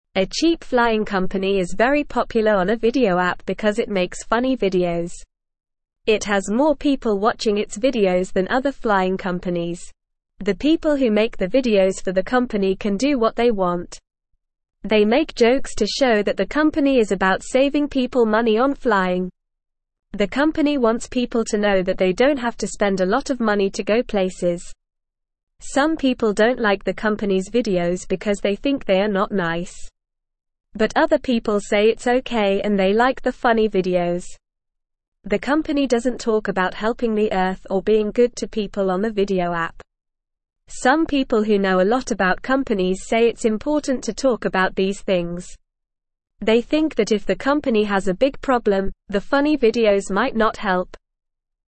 Normal
English-Newsroom-Beginner-NORMAL-Reading-Funny-Flying-Company-Saves-Money-with-Popular-Videos.mp3